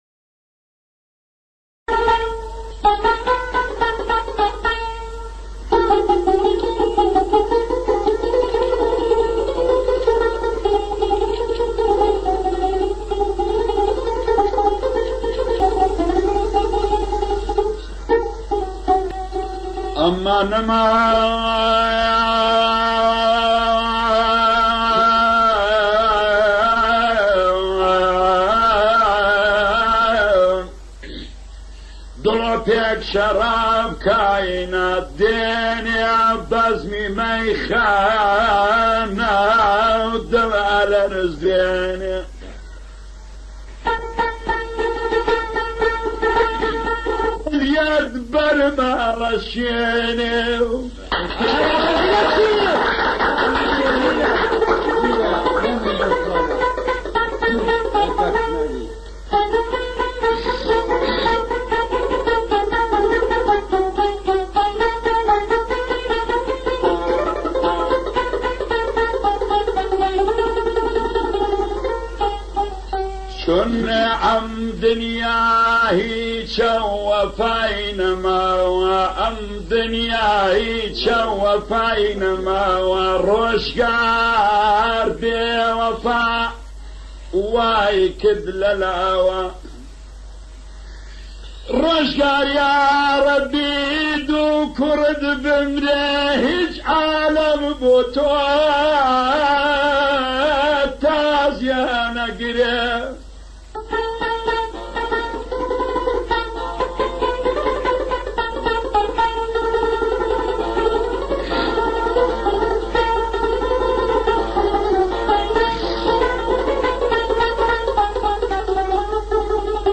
آهنگ کردی فولکلور